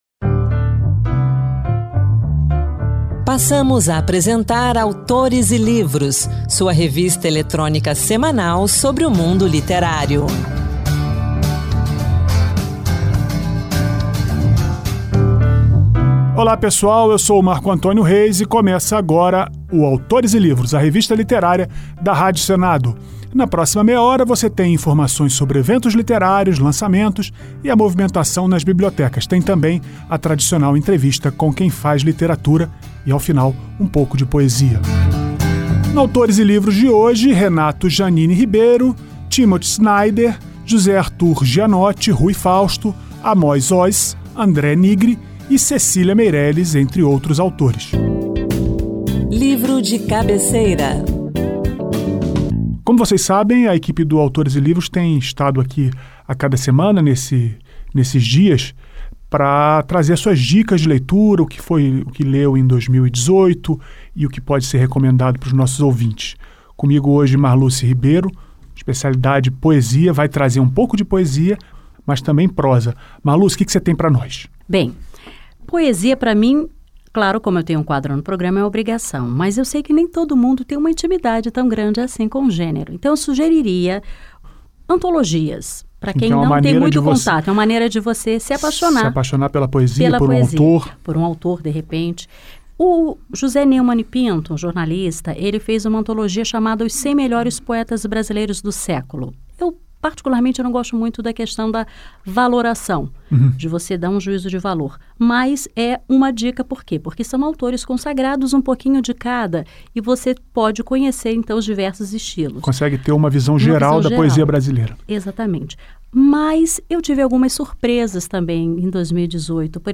Revista literária com entrevistas com autores, poesias, dicas de livros e também notícias sobre o mundo da literatura e as últimas publicações do Senado Federal